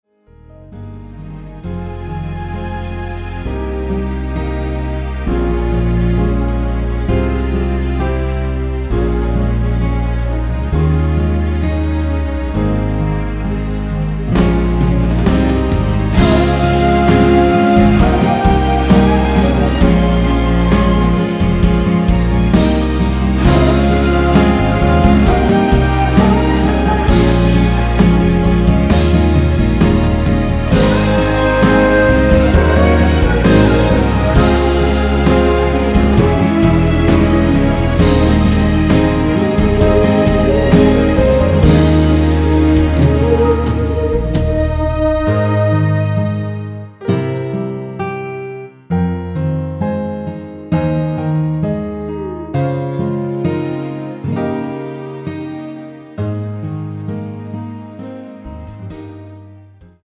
간주 코러스 외 다른 부분은 코러스 제작이 불가능 합니다.
(-2) 내린간주부분 코러스 추가된 MR 입니다.(미리듣기 참조)
F#
앞부분30초, 뒷부분30초씩 편집해서 올려 드리고 있습니다.
중간에 음이 끈어지고 다시 나오는 이유는